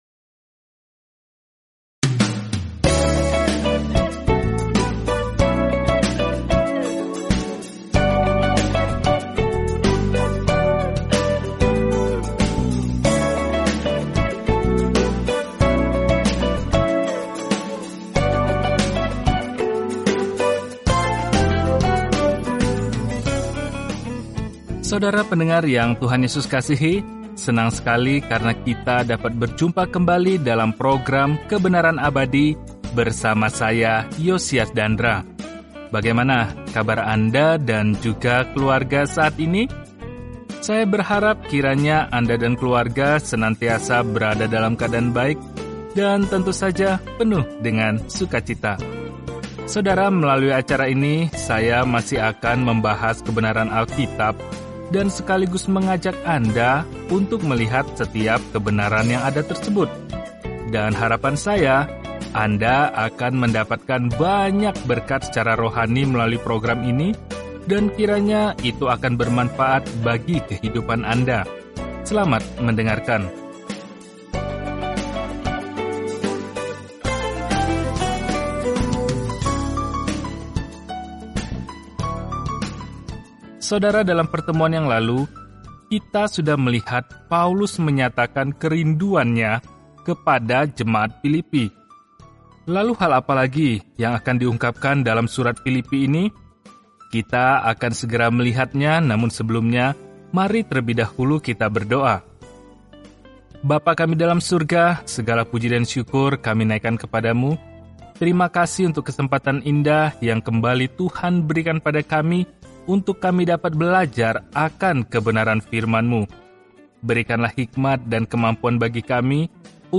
Firman Tuhan, Alkitab Filipi 1:10-13 Hari 3 Mulai Rencana ini Hari 5 Tentang Rencana ini Ucapan “terima kasih” kepada jemaat di Filipi ini memberi mereka perspektif yang menyenangkan tentang masa-masa sulit yang mereka alami dan mendorong mereka untuk dengan rendah hati melewatinya bersama-sama. Telusuri surat Filipi setiap hari sambil mendengarkan pelajaran audio dan membaca ayat-ayat tertentu dari firman Tuhan.